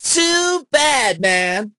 poco_kill_01.ogg